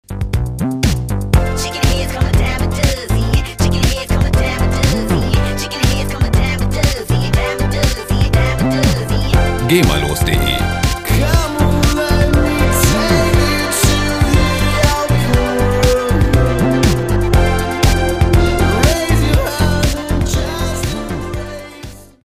Pop Loops GEMAfrei
Musikstil: Synth-Pop
Tempo: 120 bpm